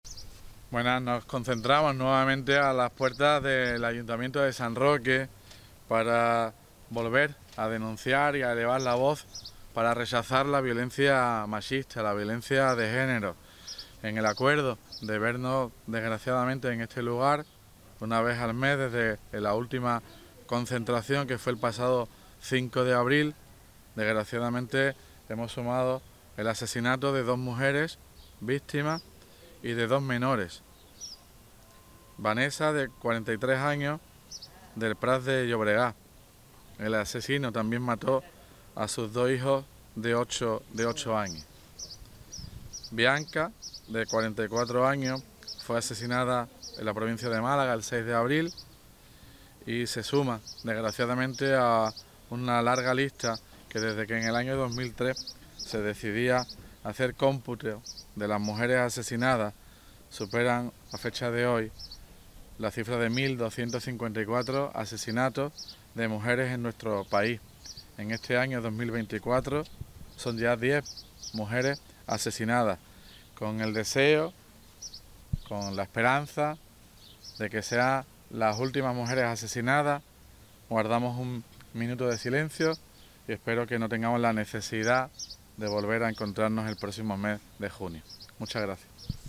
Concentración contra la violencia de género en la Plaza de las Constituciones
Numerosas personas se han concentrado este mediodía en la Plaza de las Constituciones para condenar la violencia de género y en solidaridad con sus víctimas, especialmente con las dos mujeres y dos menores asesinados en España el mes de abril.
MINUTO SILENCIO VIOLENCIA TOTAL ALCALDE.mp3